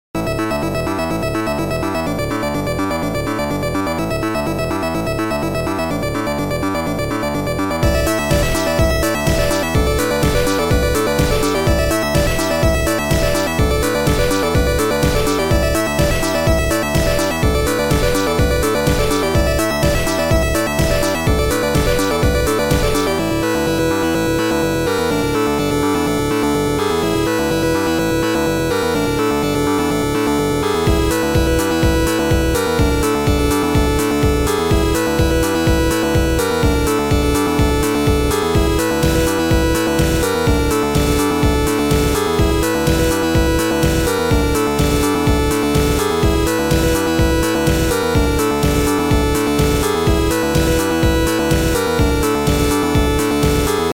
Music ( Noisetracker/Protracker )